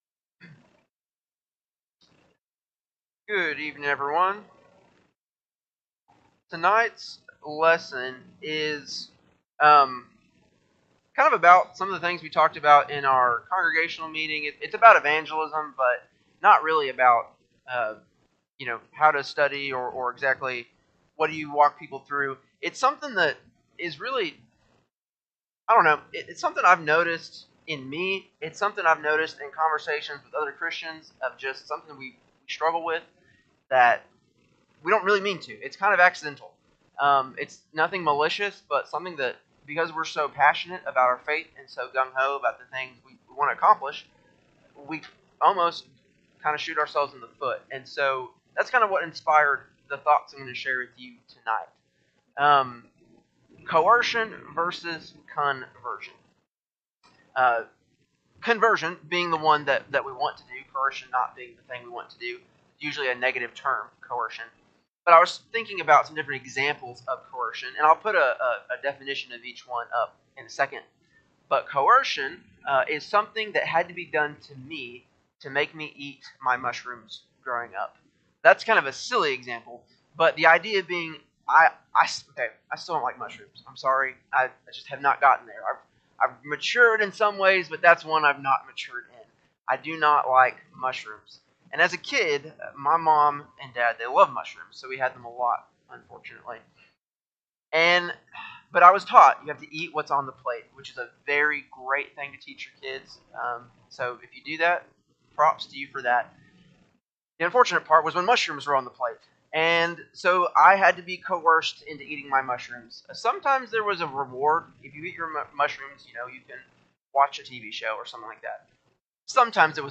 Ethical Issues / Contemporary Concerns , Evangelism , Outreach , Sunday PM Sermon
12-8-24-Sunday-PM-Sermon.mp3